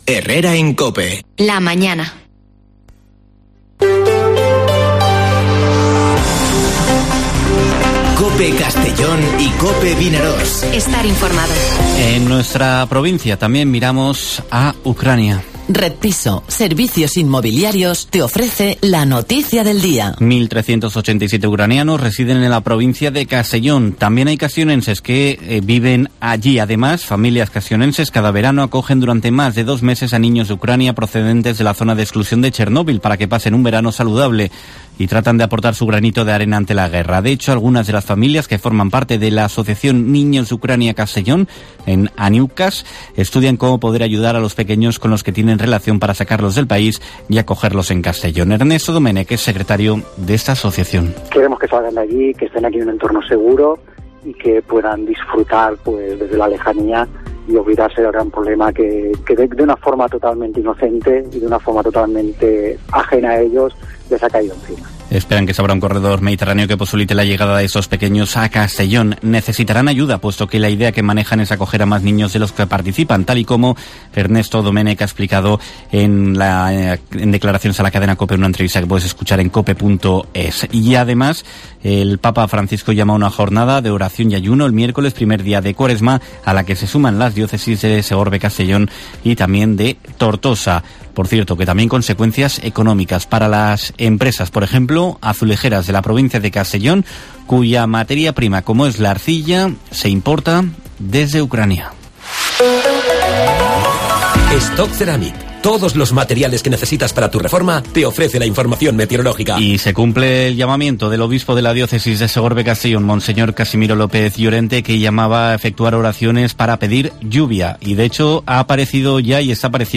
Informativo Herrera en COPE en la provincia de Castellón (25/02/2022)